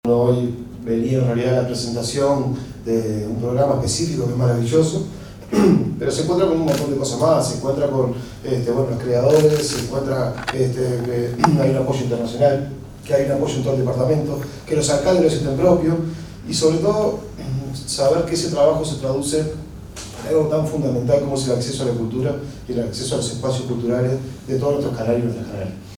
pedro_irigoin_coordinador_del_gabinete_institucional.mp3